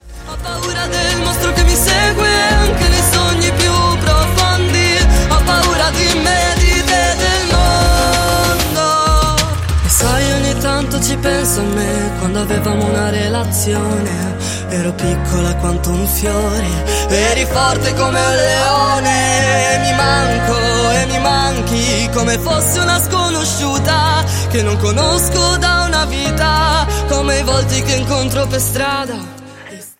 POP  (02.35)